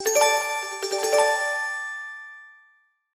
SMSに最適な可愛いベル音の通知音です。